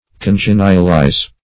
\Con*gen"ial*ize\